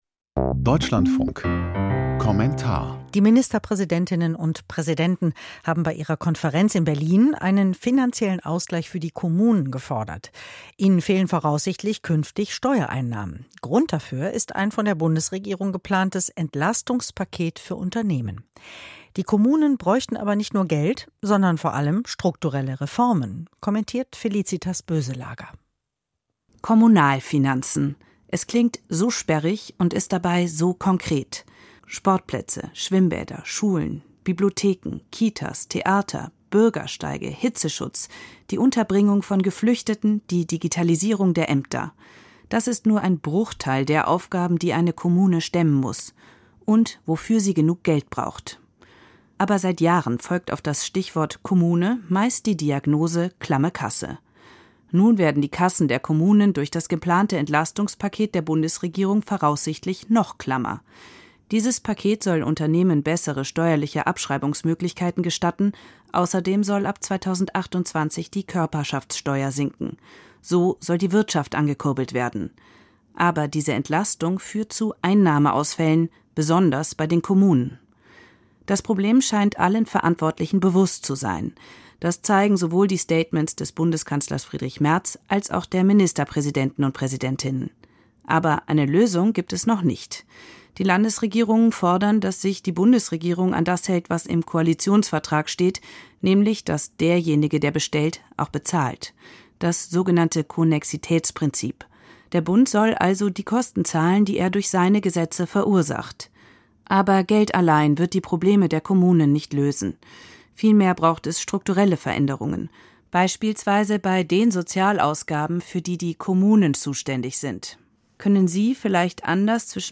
Kommentar zur Ministerpräsidentenkonferenz: Klamme Kommunen brauchen Reformen